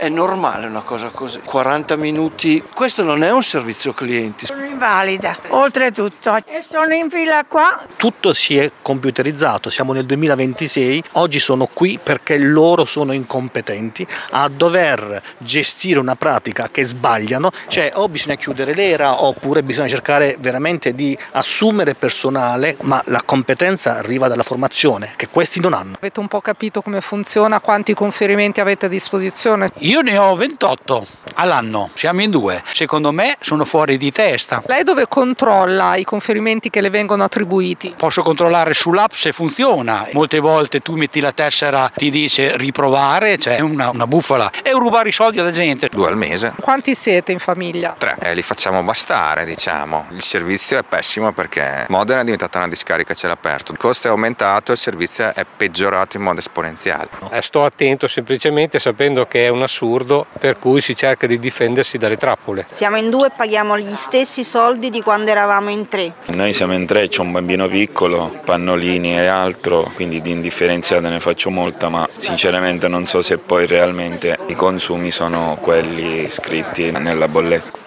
In fila al freddo davanti a Hera, le interviste
VOX-HERA-CLIENTI.mp3